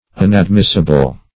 Search Result for " unadmissible" : The Collaborative International Dictionary of English v.0.48: Unadmissible \Un`ad*mis"si*ble\, Unadmittable \Un`ad*mit"ta*ble\, a. Inadmissible.